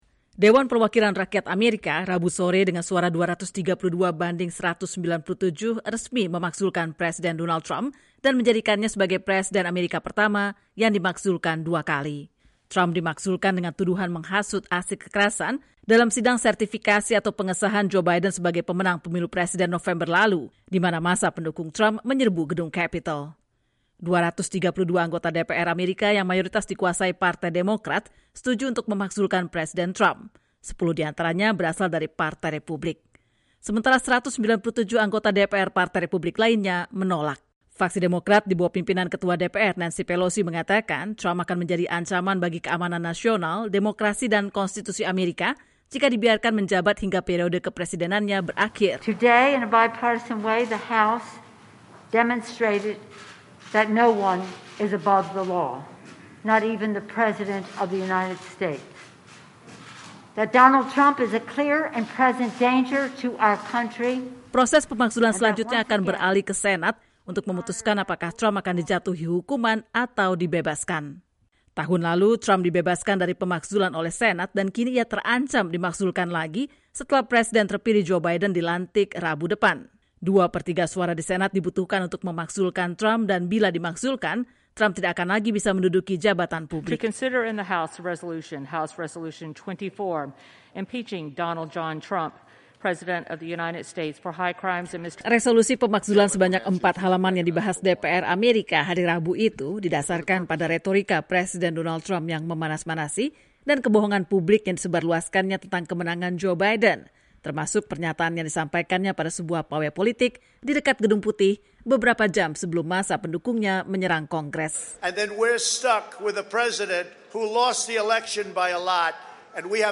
DPR Amerika pada hari Rabu (13/1) resmi memakzulkan Presiden Donald Trump dan menjadikannya presiden pertama Amerika yang dimakzulkan dua kali. Berikut laporannya.